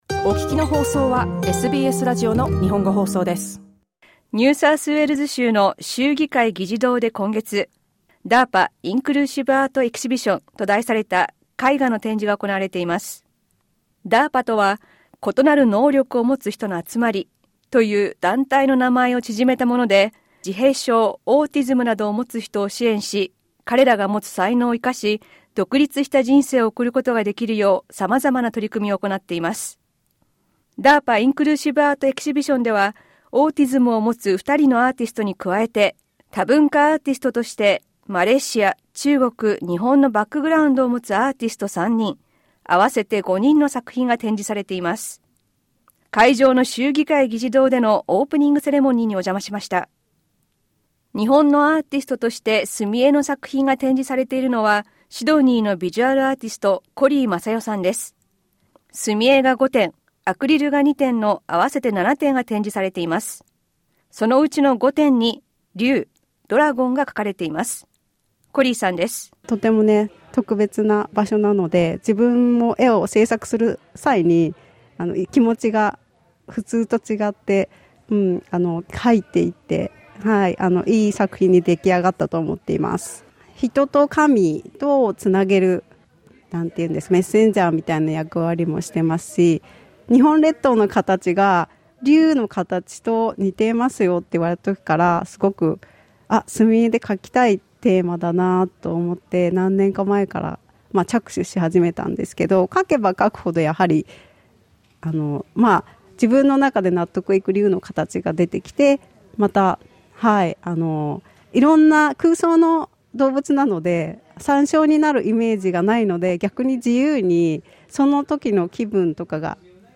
展示のオープニングでお話を聞きました。